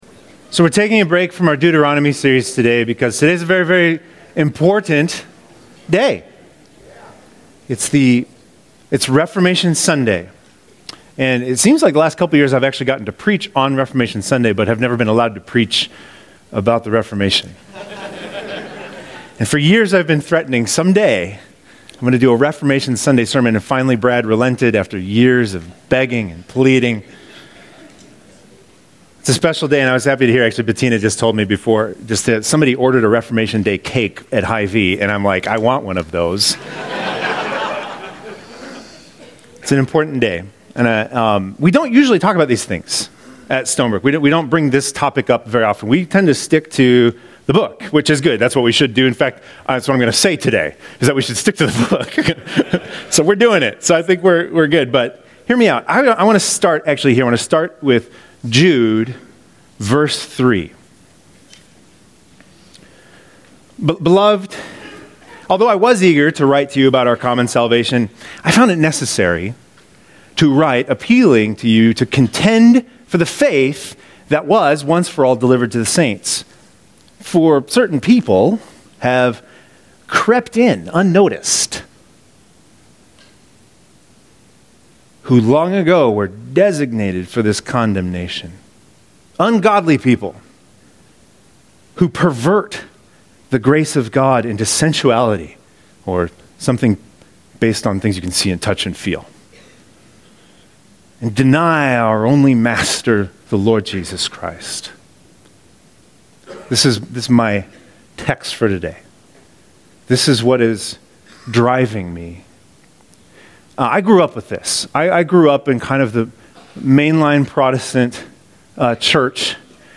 As I was preparing for this message, I started wondering how many of us had a working definition of “Protestant”.